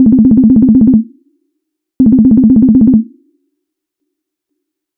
Calls 🤙